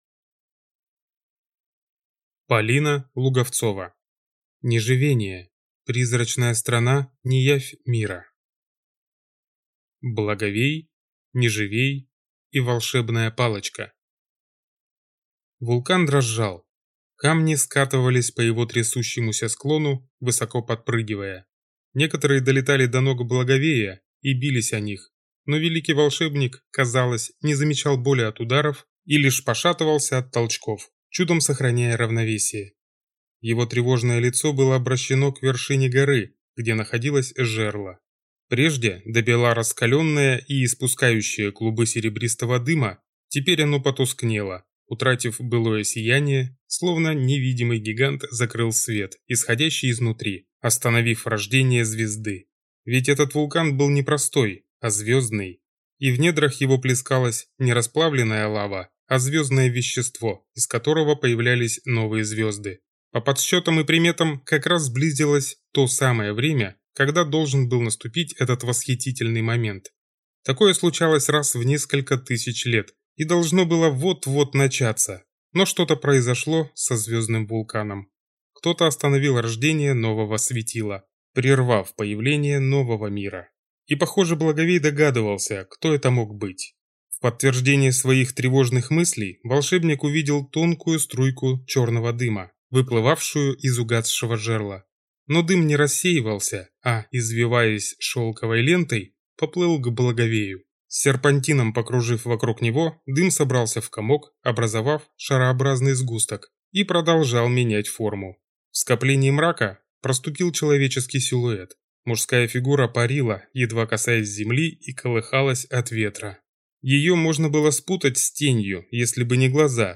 Aудиокнига Неживения